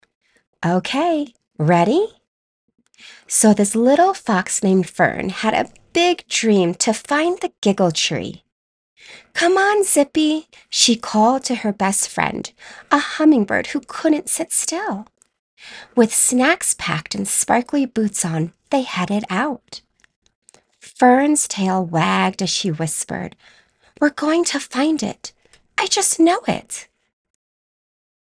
Children's Story - Playful, Nurturing, Real Mom Energy.mp3
Middle Aged